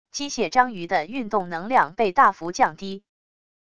机械章鱼的运动能量被大幅降低wav音频